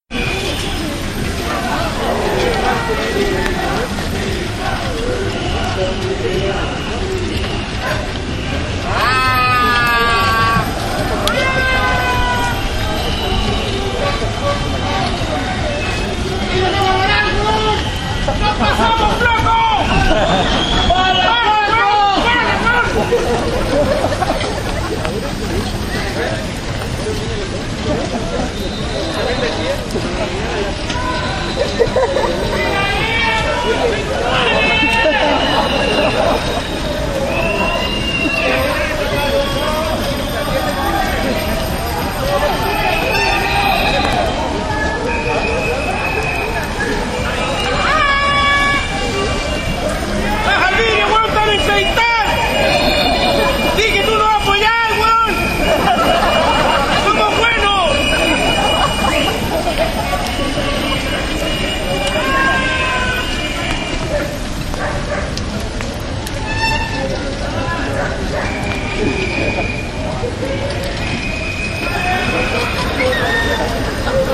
示威活动 " Manifestación (2)
描述：2014年6月，智利自行车运动员抗议
Tag: 游行 示威 呼喊 政治 抗议 游行 自行车